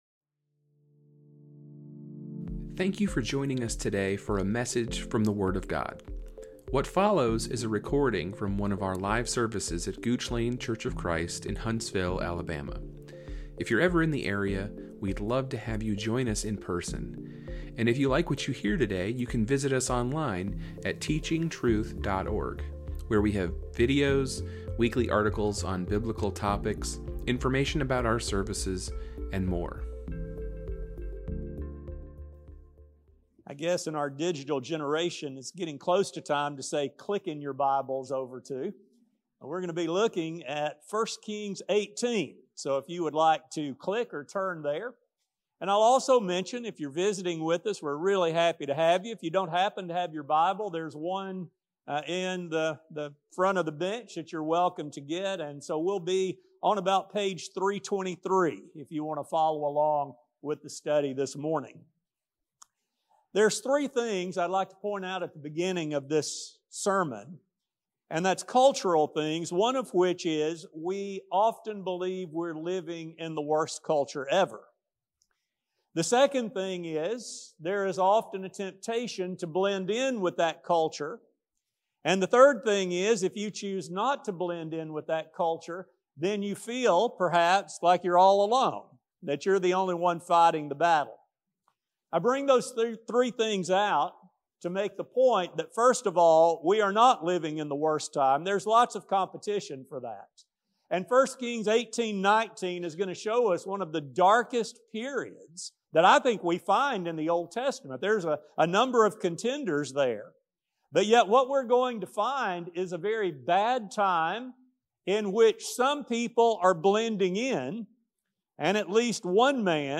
After comparing ancient and modern times, we will learn how the people of God can keep faith intact, though in the minority. A sermon